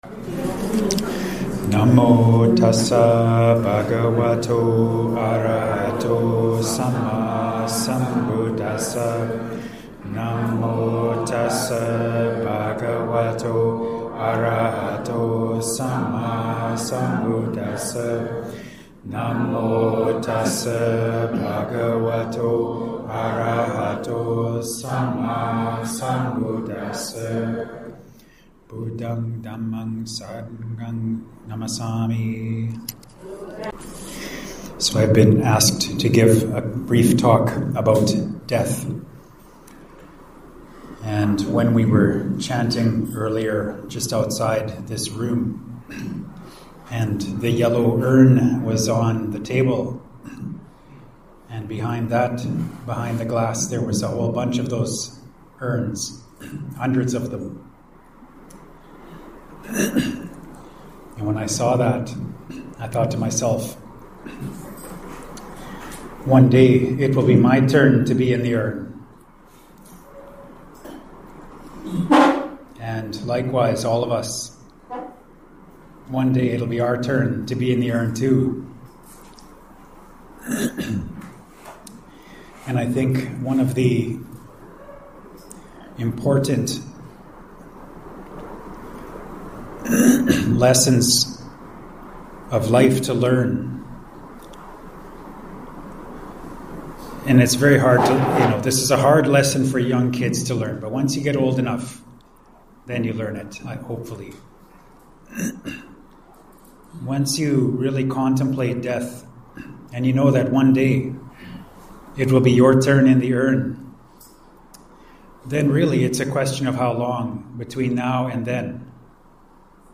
Here is a 9-min recorded audio-only Dhamma Talk, recorded at Seck Kia Eenh monastery, Melaka, Malaysia